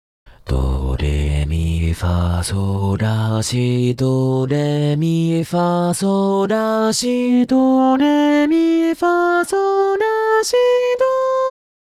【説明】 ： C4以上が裏声になる、特異な音源です。